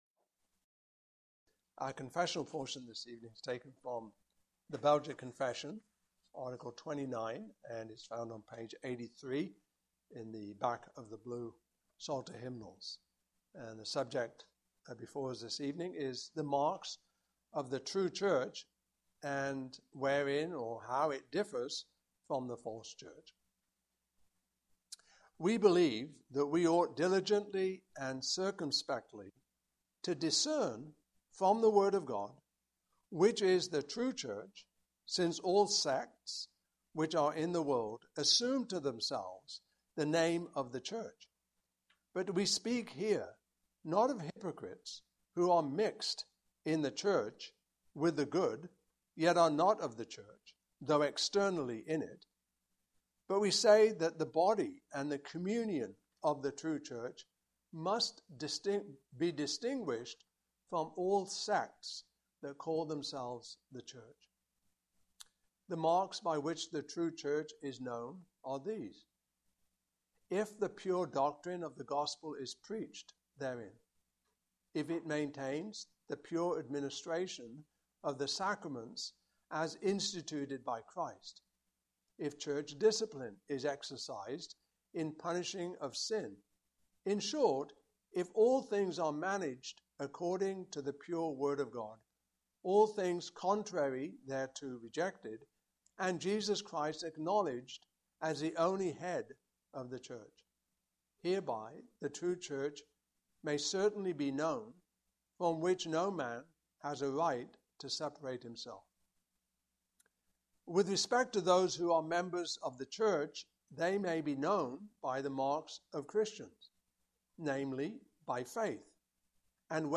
Passage: Philippians 3:1-11 Service Type: Evening Service